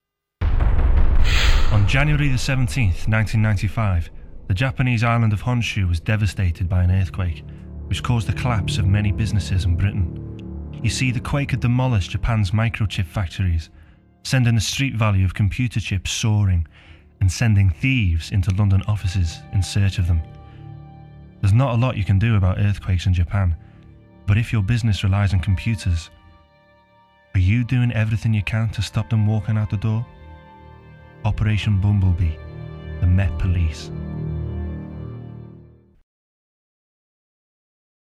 Voice Reel
MET Police - Warm, Clear, Informative